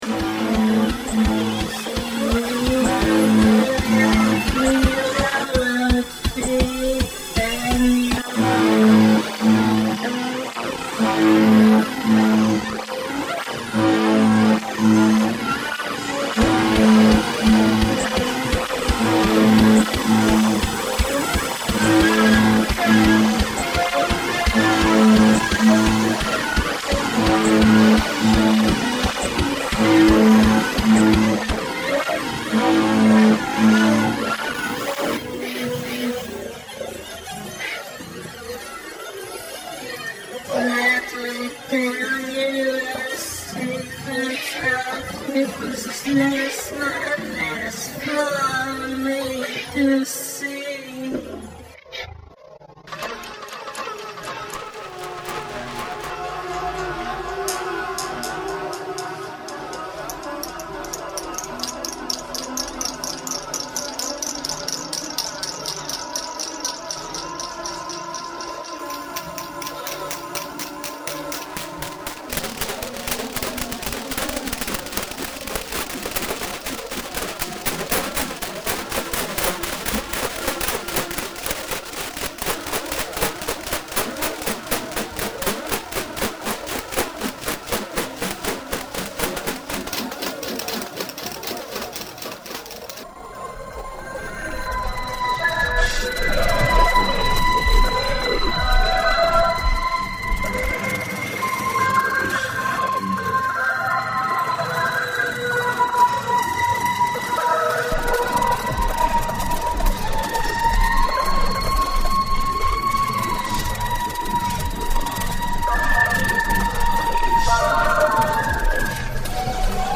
It was making me all shivery.